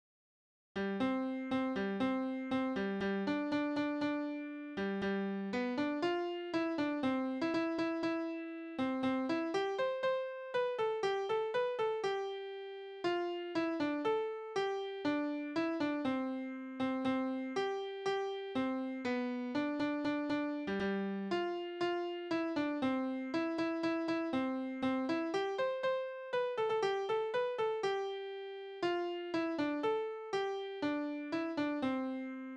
Tonart: C-Dur
Taktart: 2/4, 4/4
Tonumfang: Undezime
Besetzung: vokal